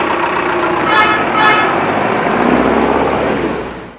Truck Sound